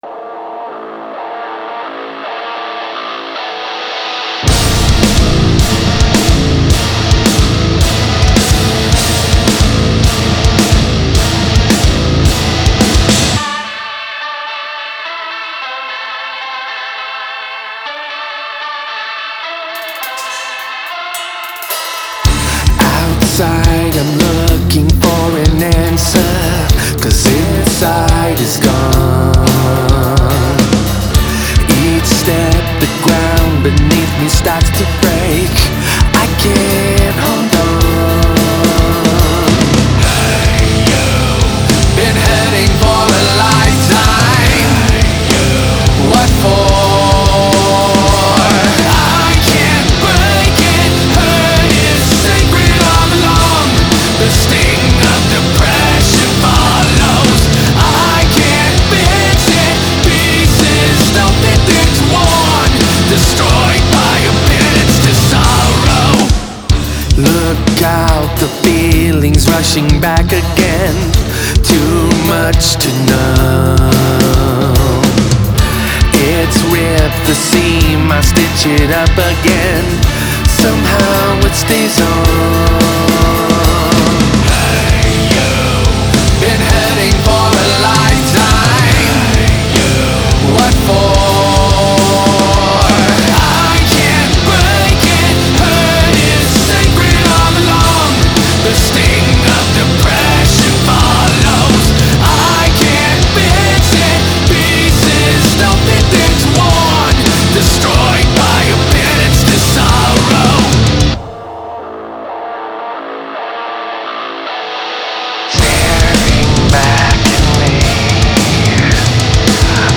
نیو متال
آلترنتیو متال